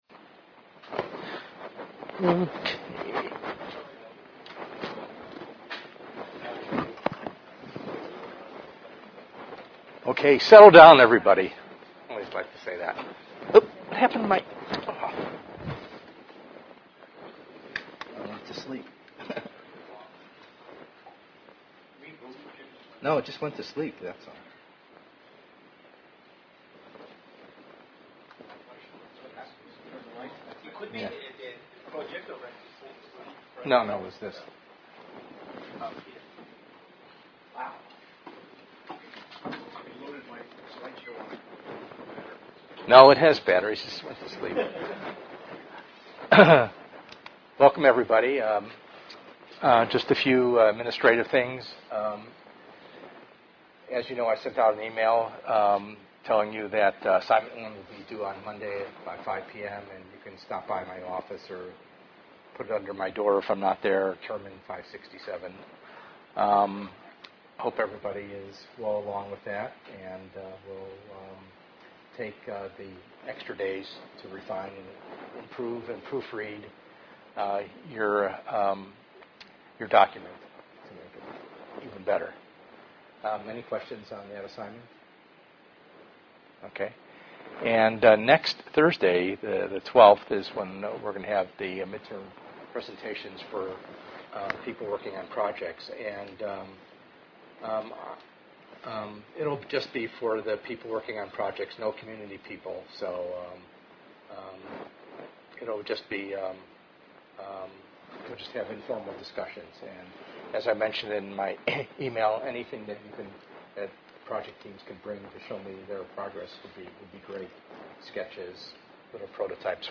ENGR110/210: Perspectives in Assistive Technology - Lecture 5b